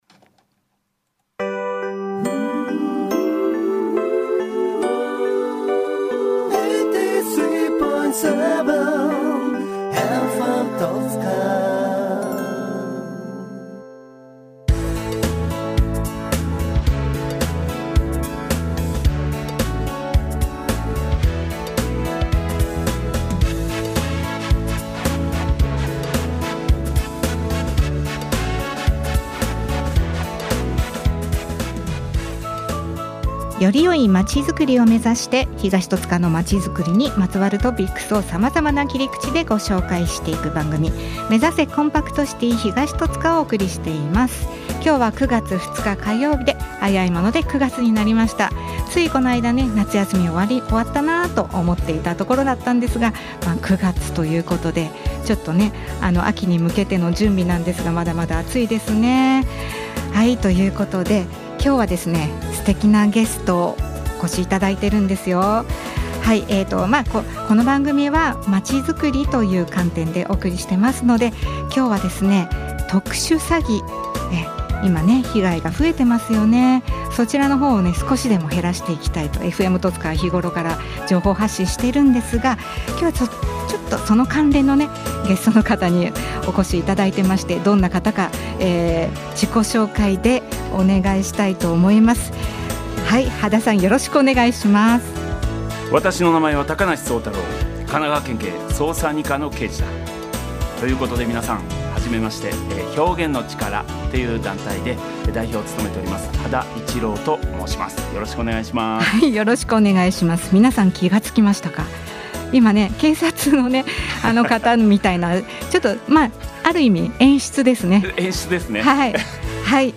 「目指せ！コンパクトシティ東戸塚」放送音源